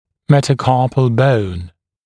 [ˌmetə»kɑːpl bəun][ˌмэтэ’ка:пл боун]пястная кость